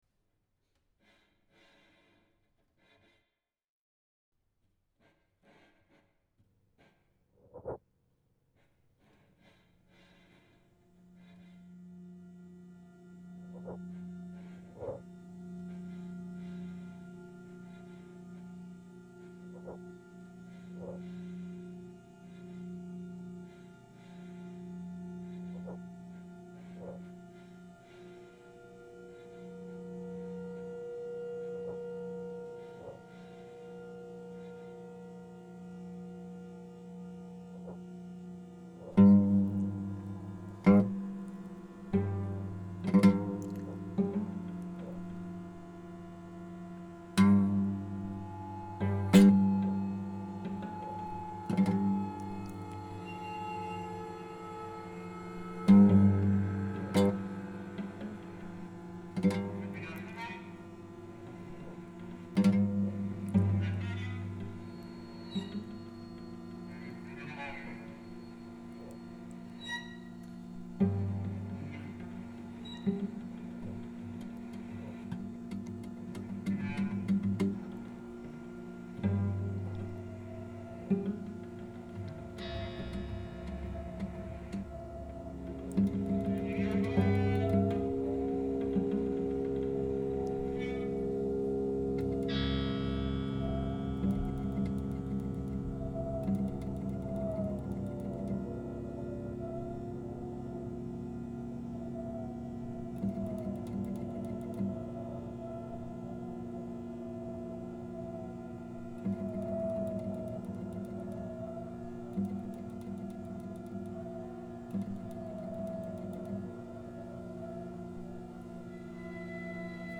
Die elektroakustische Komposition